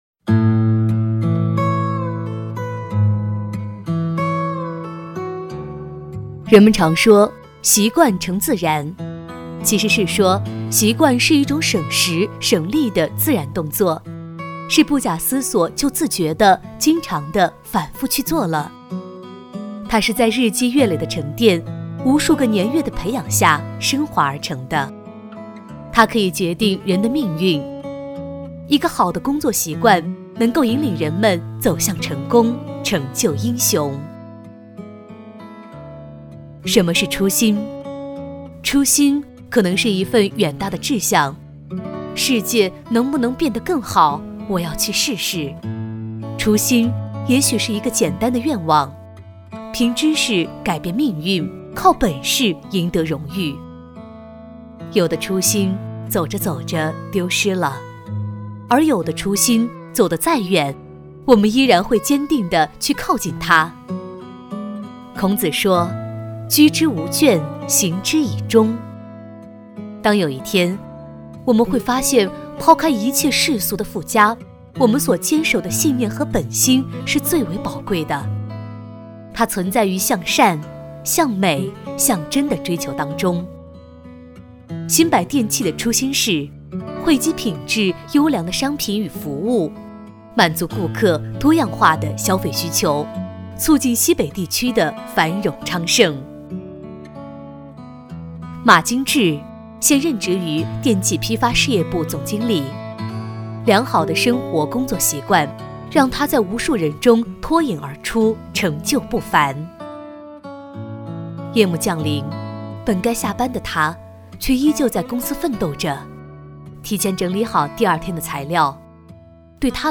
女国语129